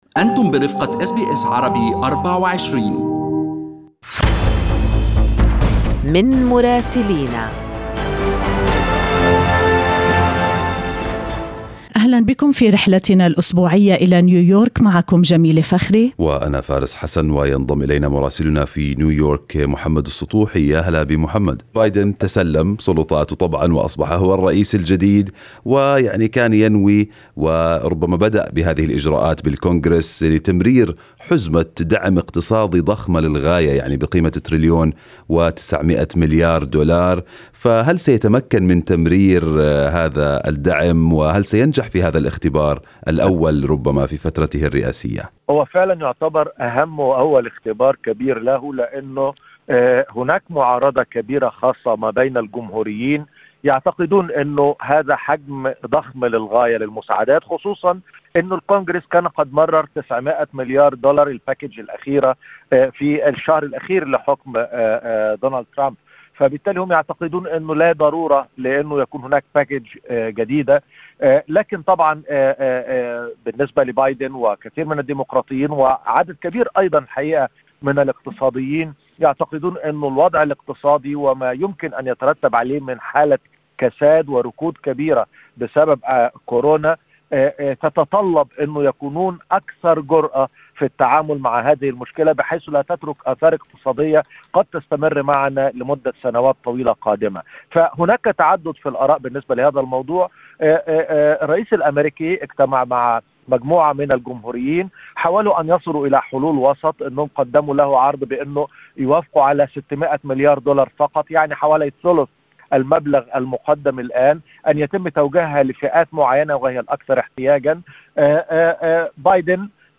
يمكنكم الاستماع إلى تقرير مراسلنا في نيويورك بالضغط على التسجيل الصوتي أعلاه.